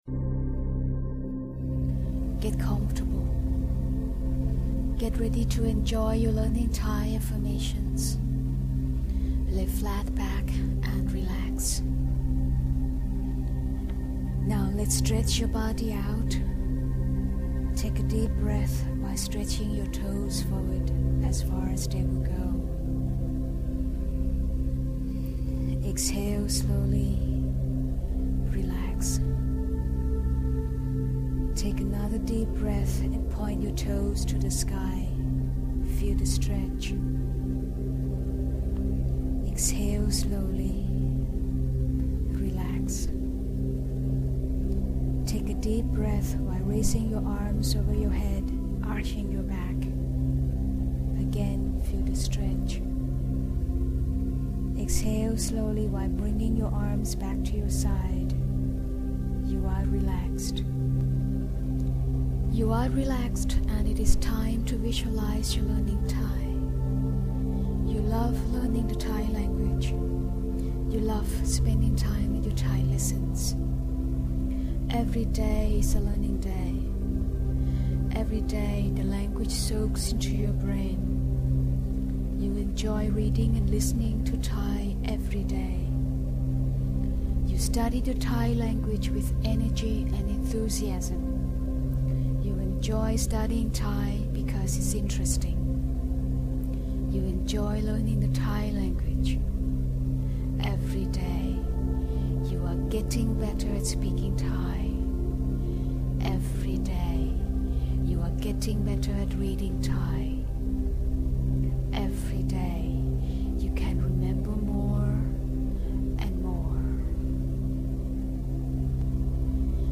So in the hopes that you [and me me me] drop off, there is no real ending; the affirmation keeps on rolling.